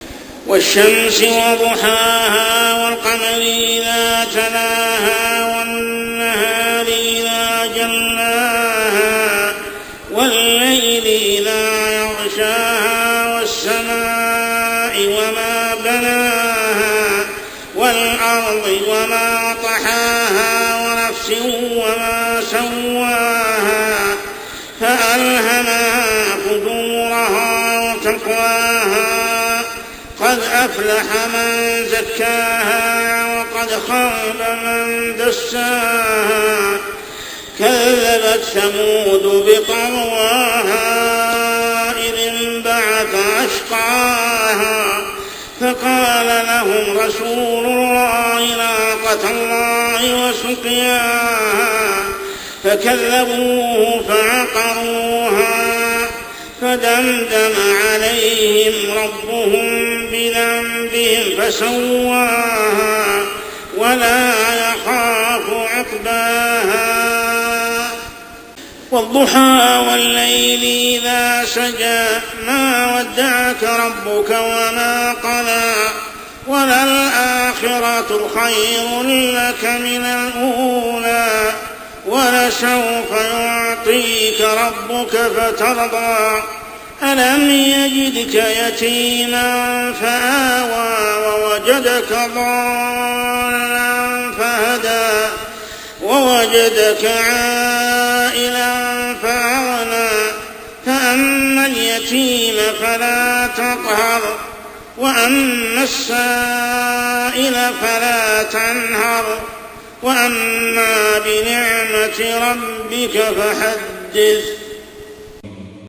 عشائيات شهر رمضان 1426هـ سورة الشمس و الضحى كاملة | Isha prayer Surah Ash-Shams and Ad-Duhaa > 1426 🕋 > الفروض - تلاوات الحرمين